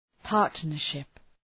Προφορά
{‘pɑ:rtnər,ʃıp}